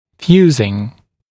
[‘fjuːzɪŋ][‘фйу:зин]плавление; расплавление, зарастание